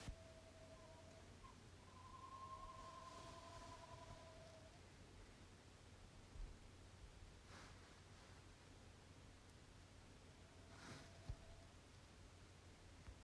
Hvilken ugle?
Synes ikke dette ligner på den vanlige kattuglelyden?
Ja, dette er en kattugle.